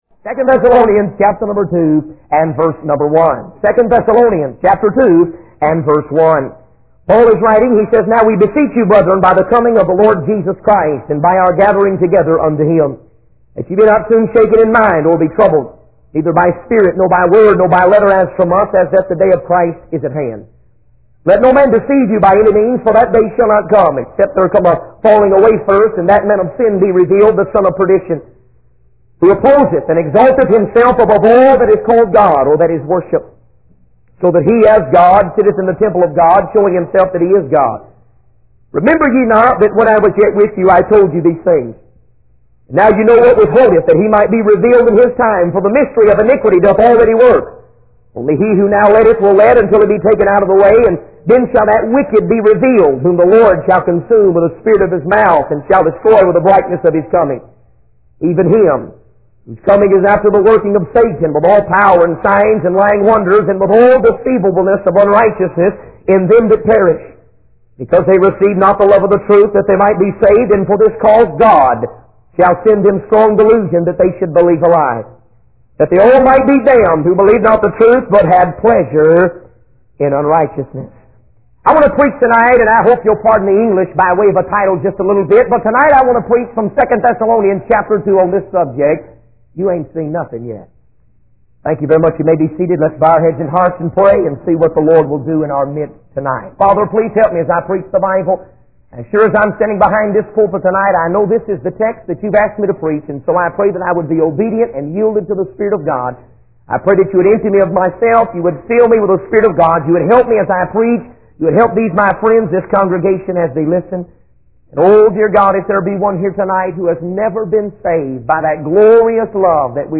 In this sermon, the preacher shares a personal experience of encountering a young girl who had been attending the church services consistently. The preacher emphasizes the importance of focusing on the word of God rather than worldly distractions. He warns against being deceived by false prophets and encourages believers to stay rooted in the Bible.